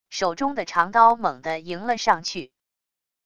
手中的长刀猛的迎了上去wav音频生成系统WAV Audio Player